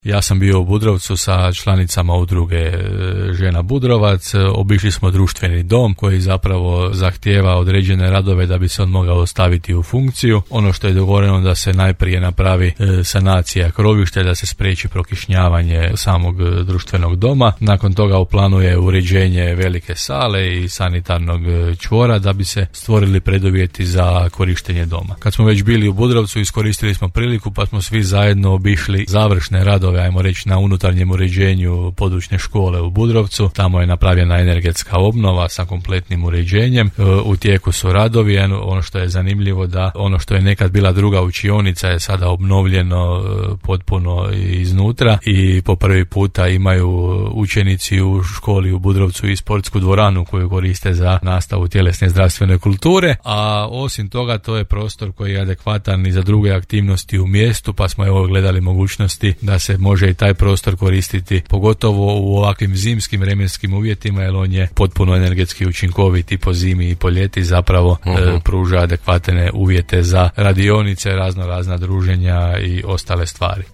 – rekao je gradonačelnik Janči u emisiji Gradske teme.